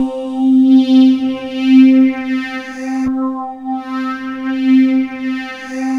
Index of /90_sSampleCDs/USB Soundscan vol.13 - Ethereal Atmosphere [AKAI] 1CD/Partition E/11-QUARTZ